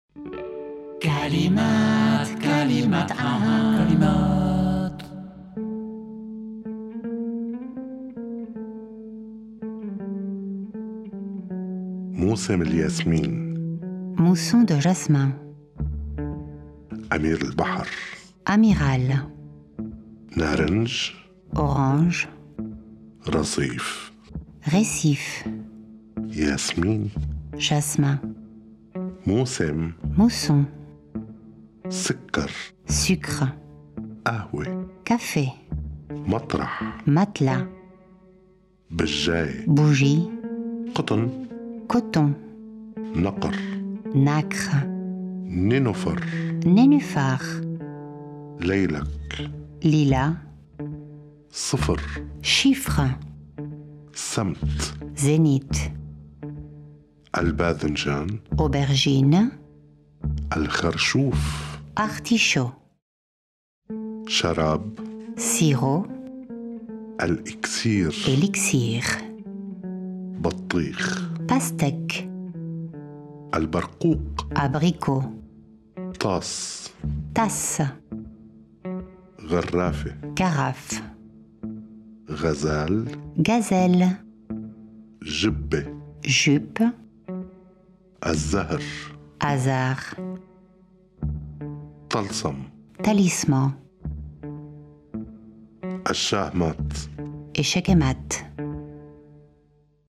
création musicale
lecture en français
lecture en arabe